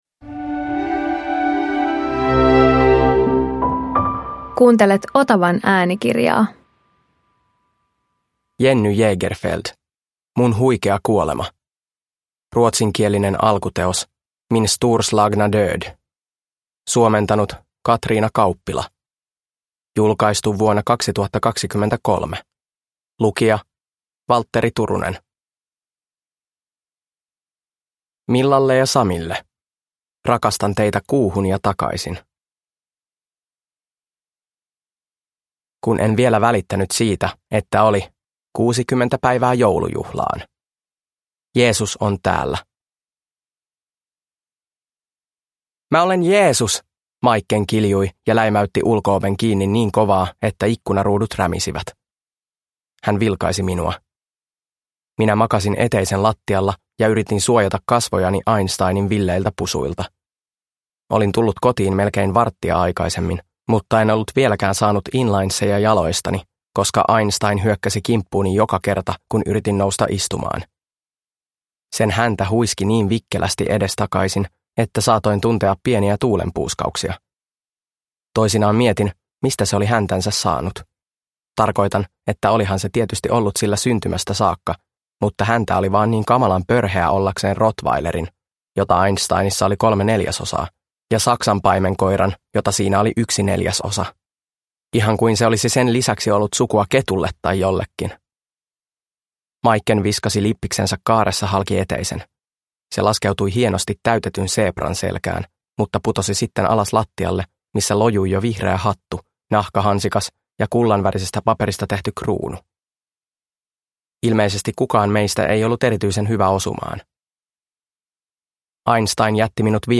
Mun huikea kuolema – Ljudbok – Laddas ner